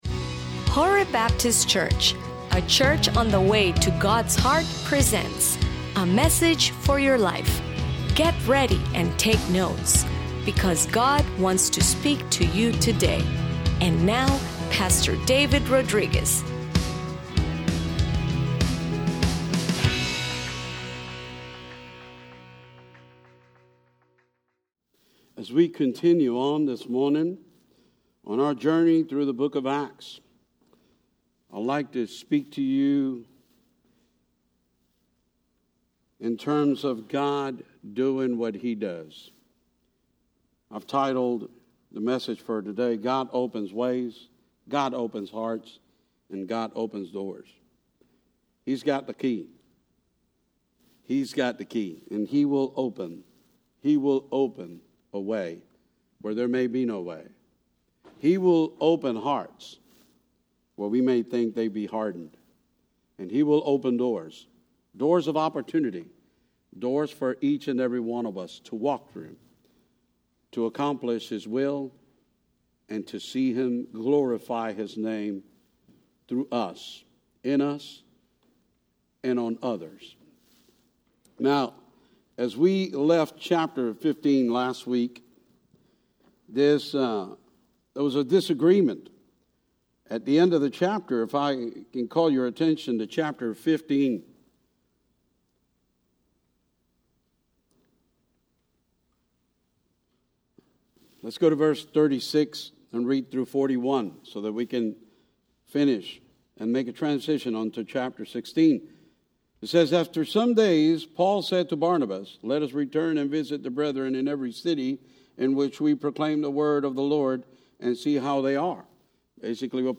Sermones-7_03_22-ingles.mp3